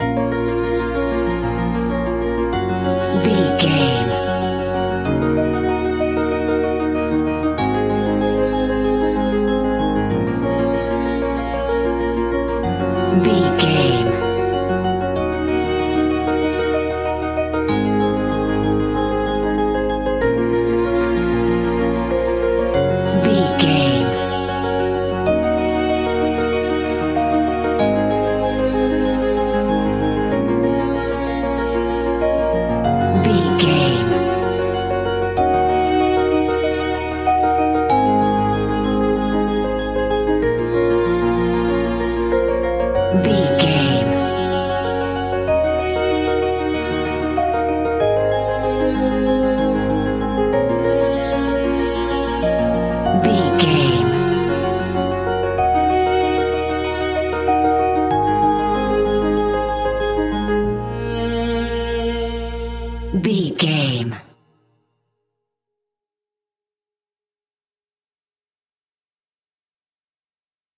royalty free music
Aeolian/Minor
B♭
dreamy
ethereal
peaceful
melancholy
hopeful
piano
violin
cello
percussion
electronic
synths
instrumentals
synth drums